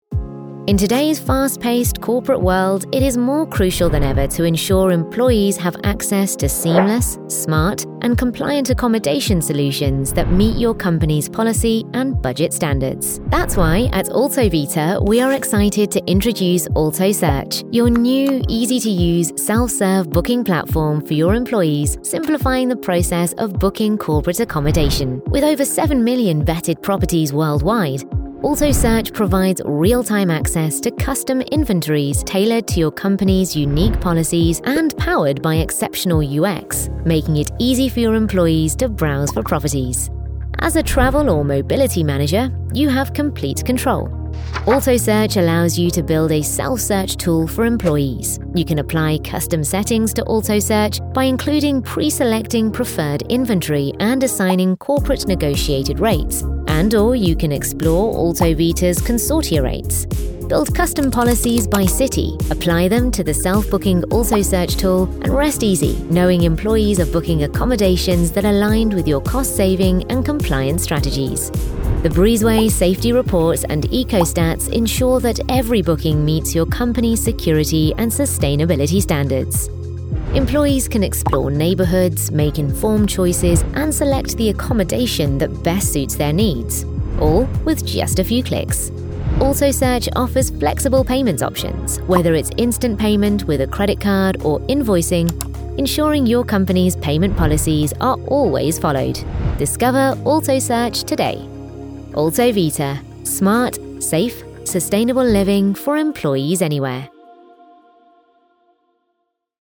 Englisch (Britisch)
Natürlich, Verspielt, Urban, Freundlich, Warm
Erklärvideo